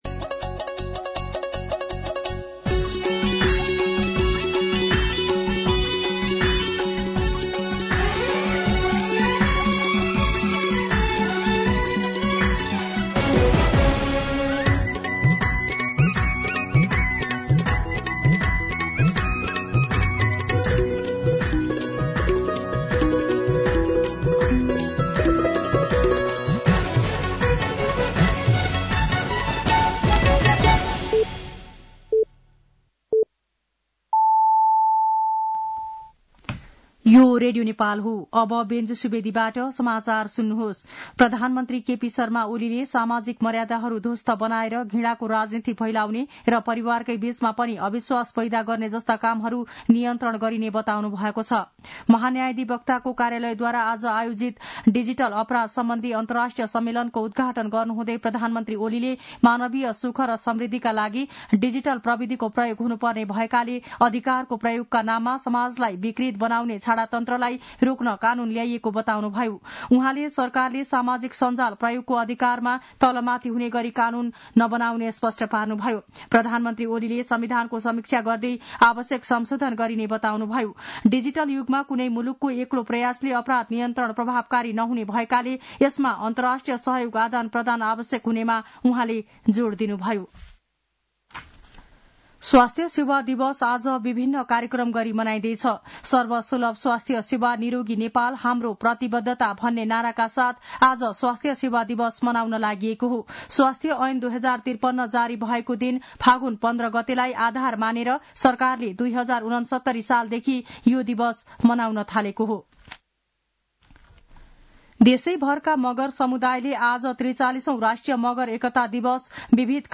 दिउँसो १ बजेको नेपाली समाचार : १६ फागुन , २०८१
1pm-News-11-15.mp3